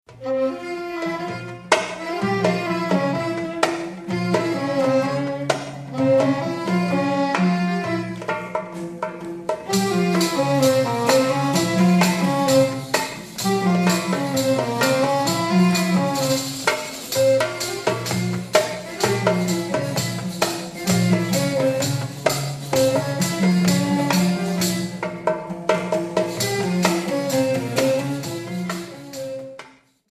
Live in India! CD
(Instrumental) Prelude in Hijaz